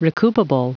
Prononciation du mot recoupable en anglais (fichier audio)
Prononciation du mot : recoupable